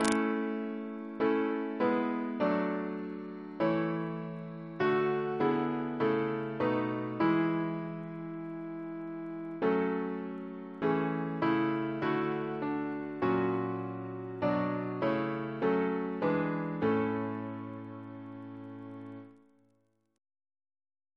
Double chant in A Composer: George J. Bennett (1863-1930) Reference psalters: RSCM: 20